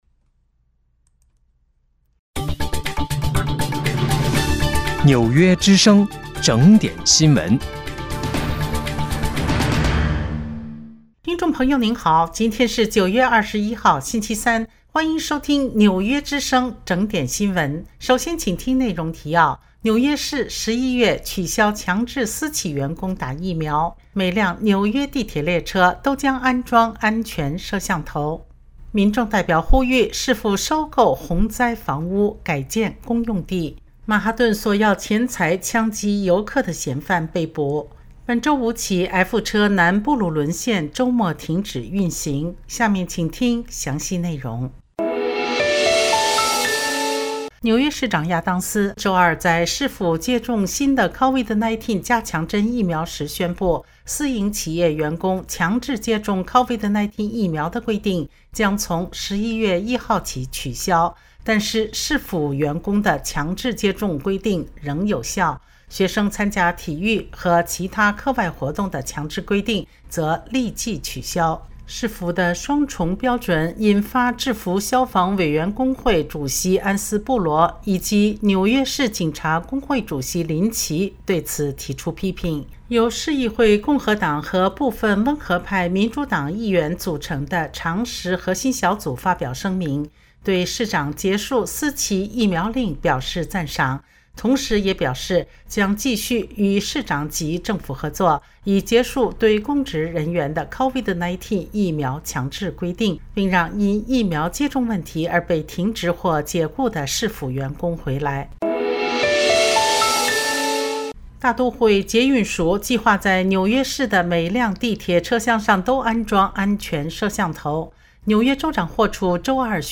9月21号(星期三)纽约整点新闻